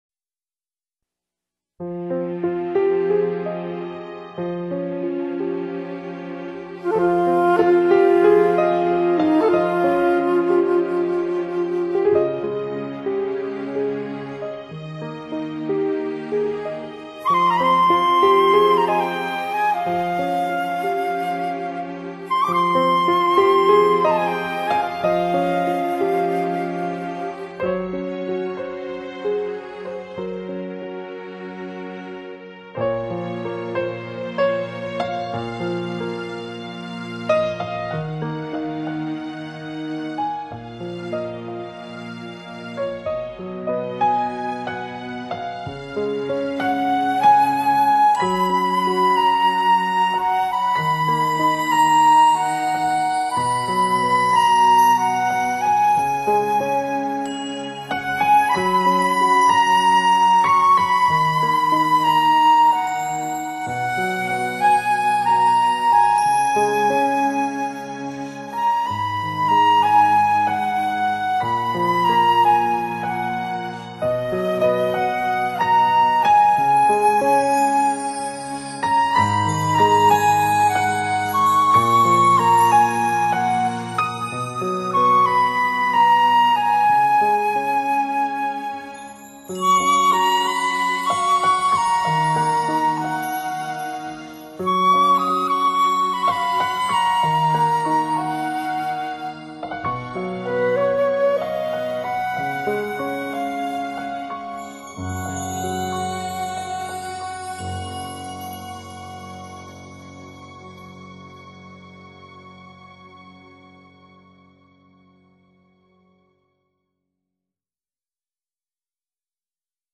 长笛
钢琴